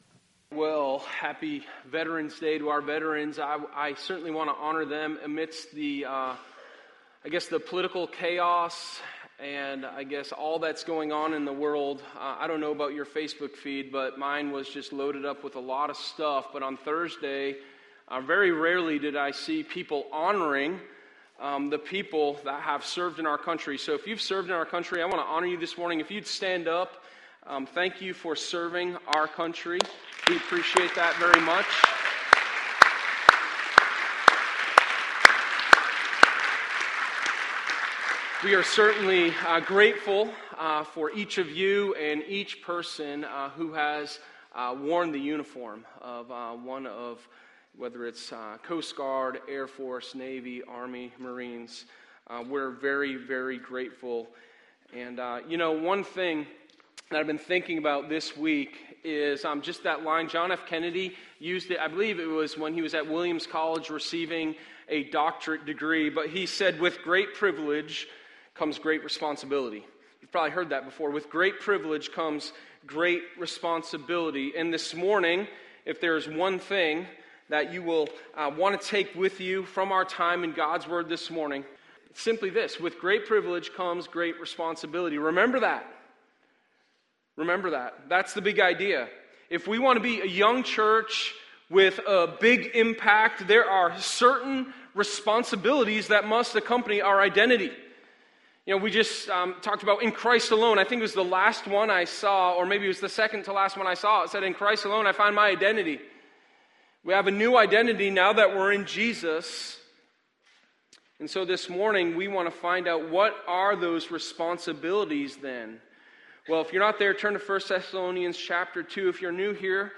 Sermon1113_4ResponsibilitiesofYourNewIdentity.mp3